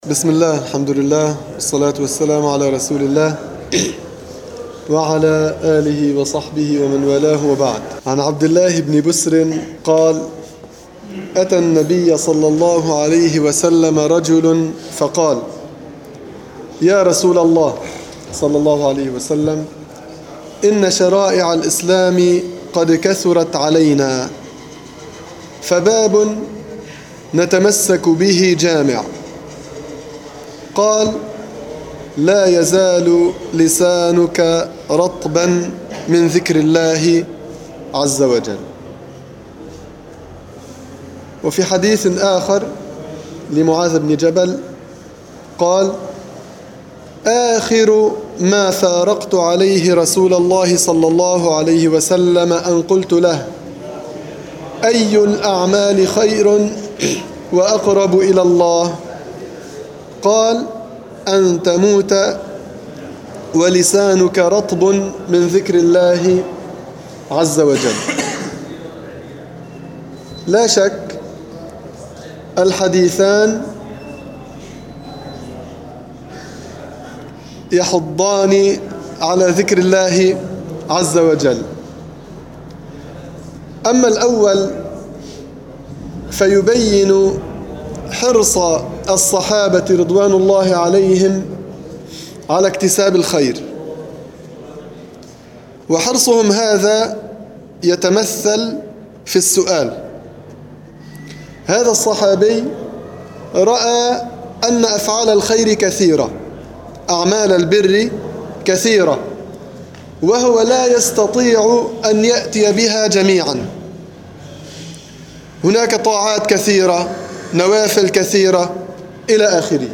دروس تفسير الحديث
في مسجد القلمون الغربي